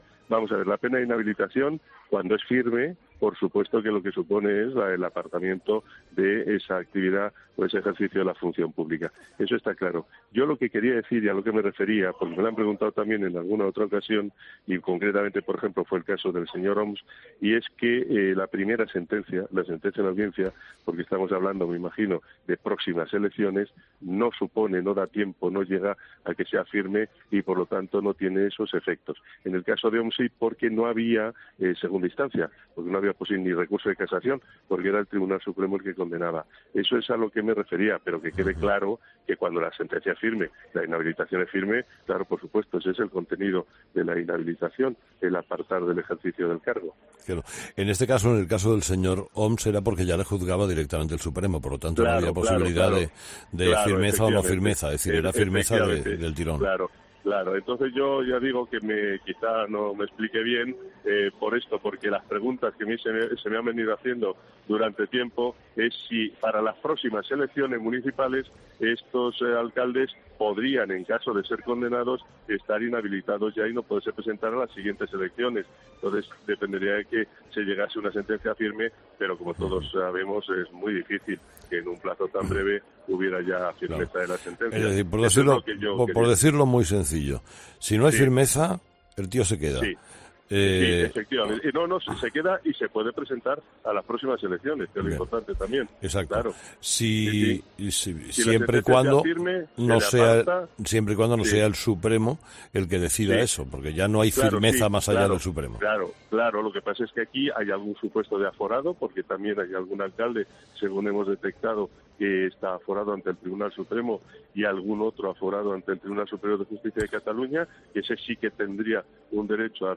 ESCUCHA LA ENTREVISTA COMPLETA AL FISCAL GENERAL DEL ESTADO, JOSÉ MANUEL MAZA